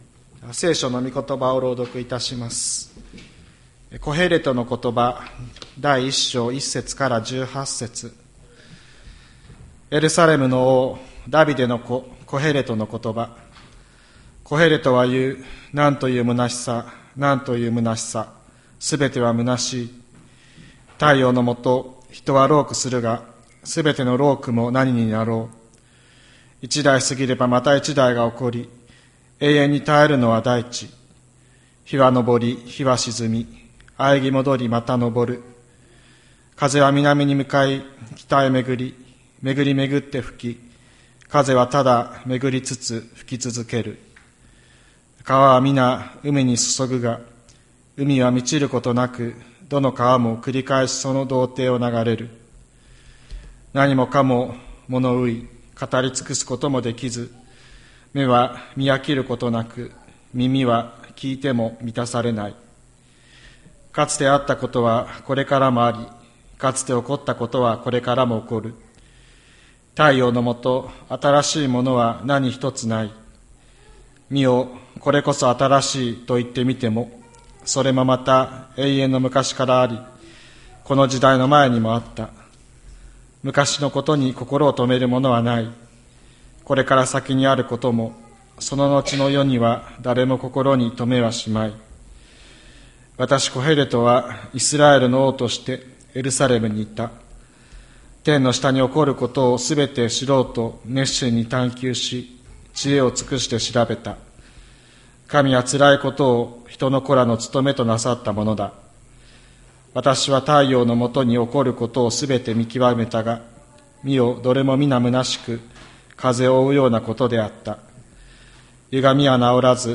2024年03月24日朝の礼拝「空しさのなかでどう生きるか」吹田市千里山のキリスト教会
千里山教会 2024年03月24日の礼拝メッセージ。